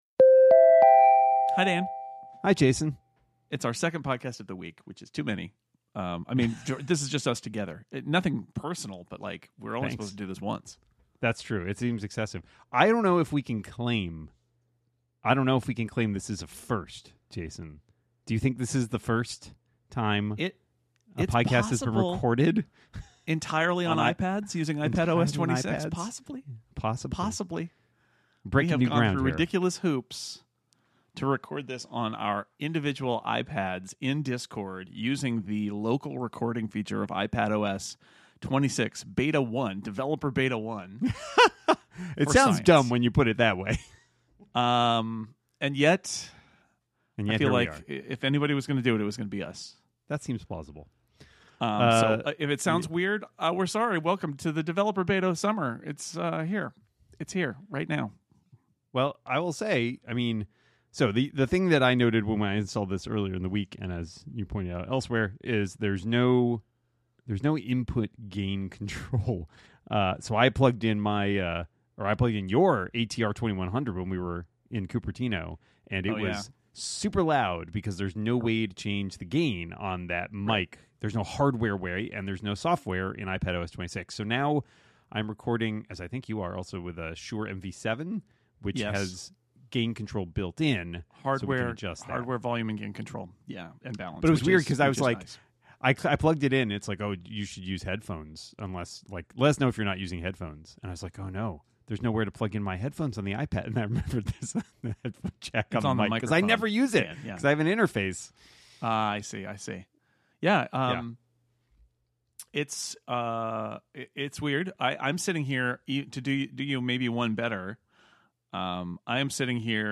You’ll be disappointed if you expect to hear anything special about it, though. We both recorded it on our usual Shure MV7 USB microphones, and it just doesn’t sound any different at all.